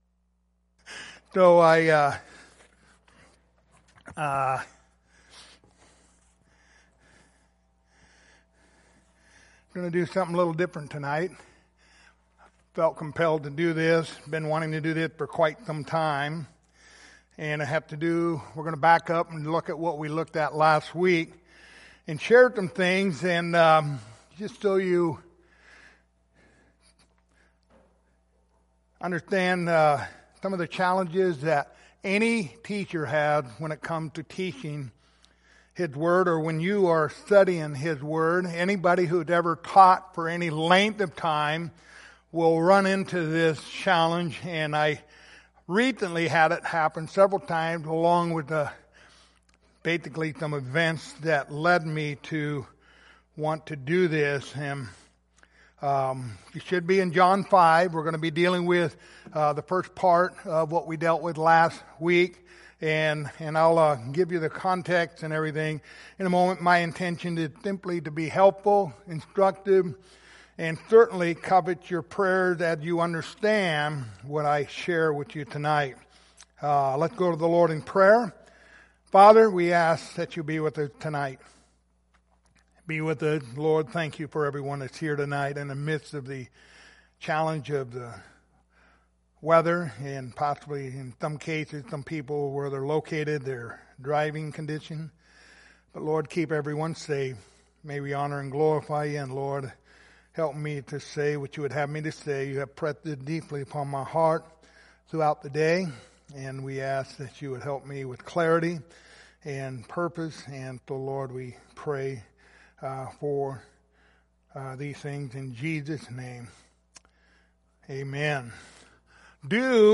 Passage: John 5:1-9 Service Type: Wednesday Evening Topics